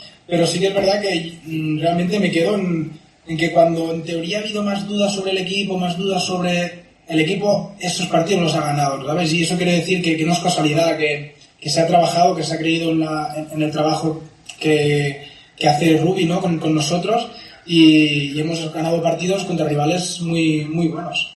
Bartra, en una entrevista con los compañeros de GolTV